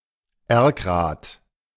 Erkrath (German pronunciation: [ˈɛʁkʁaːt]
De-Erkrath.ogg.mp3